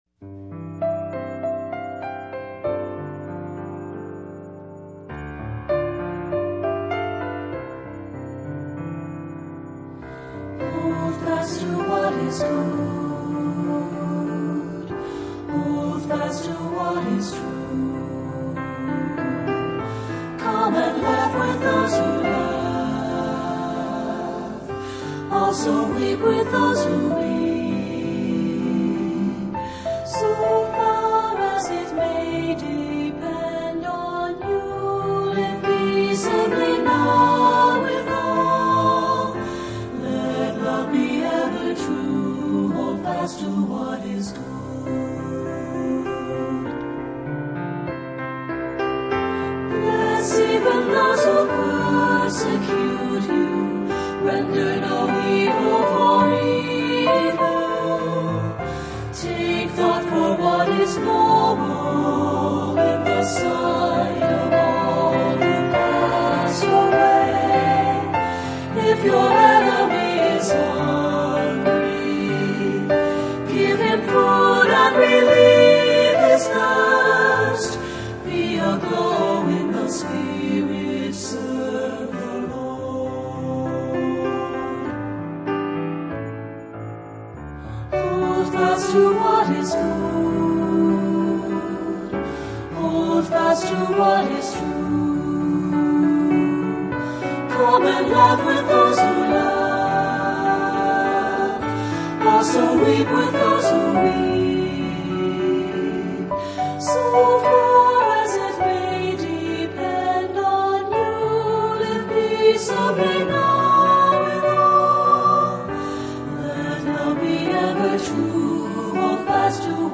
Voicing: SAT